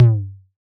Index of /musicradar/retro-drum-machine-samples/Drums Hits/Tape Path B
RDM_TapeB_SY1-Tom02.wav